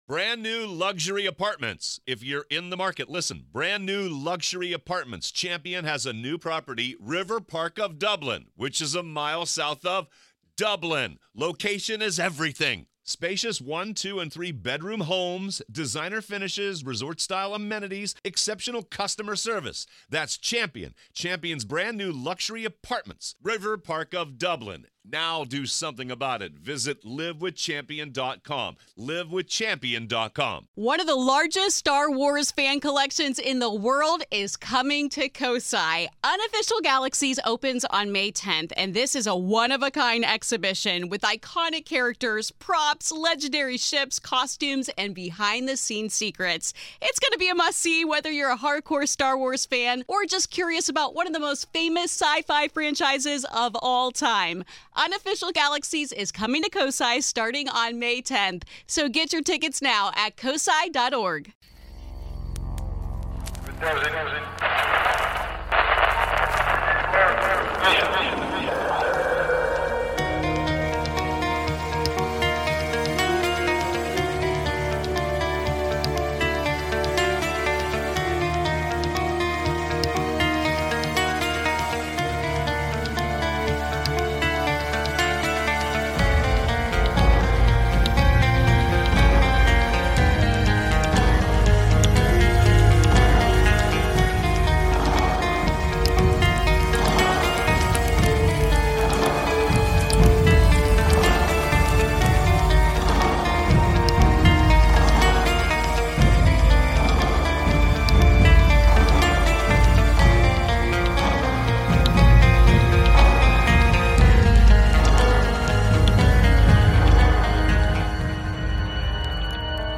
On this episode I play rebuttal calls from past submissions, a sasquatch call, a hatman call and a story about someone hiding in a truck.